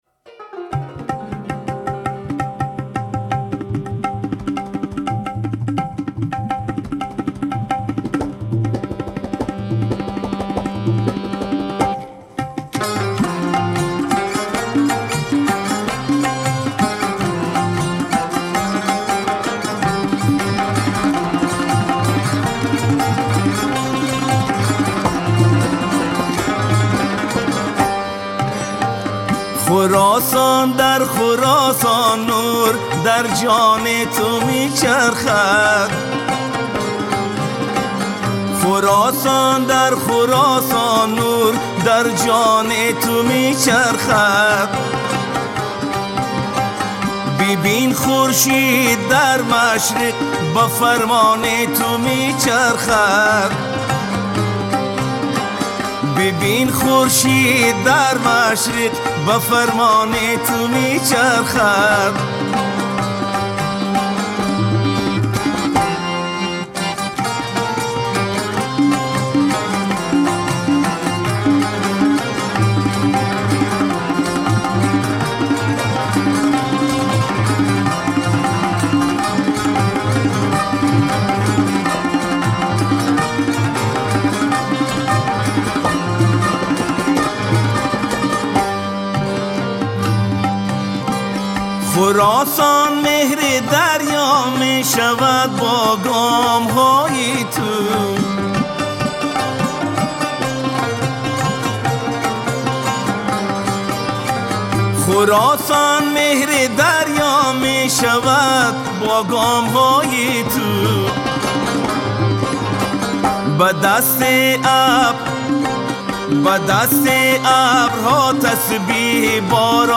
برگرفته از قوالی ها و مناقب خوانی مکتب هرات (افغانستان)